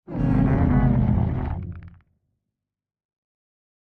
Minecraft Version Minecraft Version 1.21.4 Latest Release | Latest Snapshot 1.21.4 / assets / minecraft / sounds / mob / warden / ambient_7.ogg Compare With Compare With Latest Release | Latest Snapshot
ambient_7.ogg